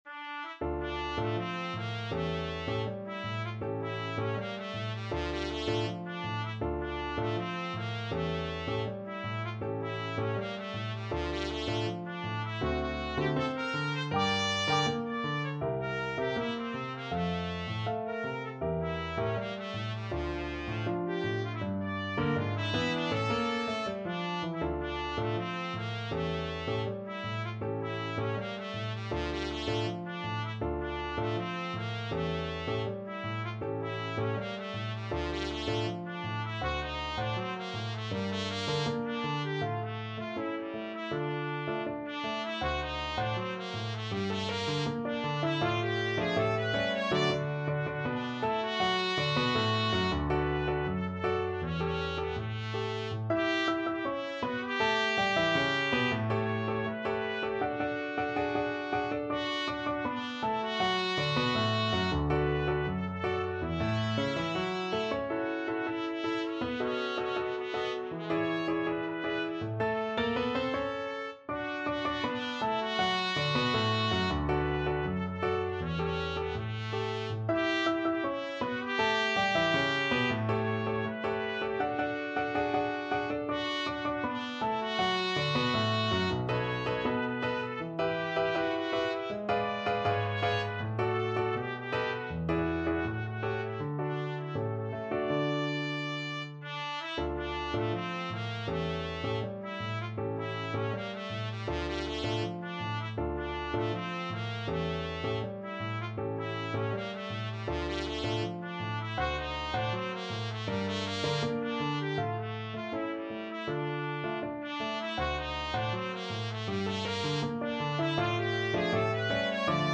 A beautiful tango melody in an A-B-A form.
=80 Grazioso
2/4 (View more 2/4 Music)
Arrangement for Trumpet and Piano
Latin and Tango Trumpet